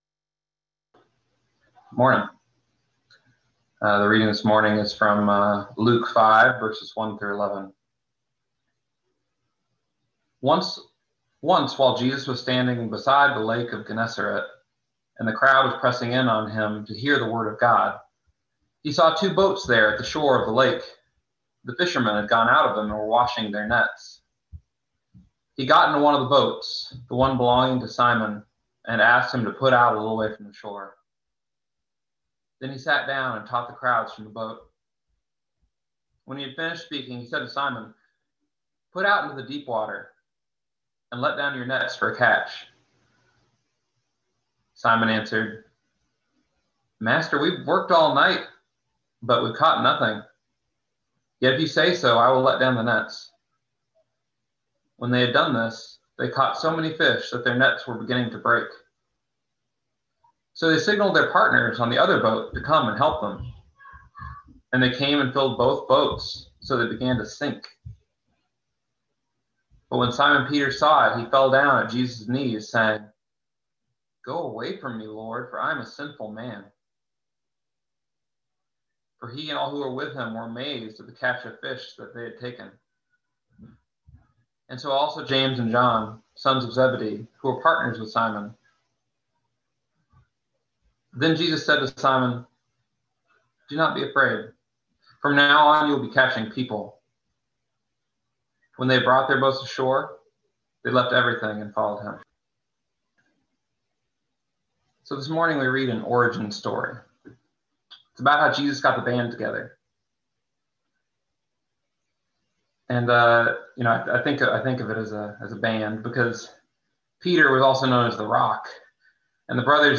Listen to the most recent message from Sunday worship at Berkeley Friends Church, “Into the Deep Water.”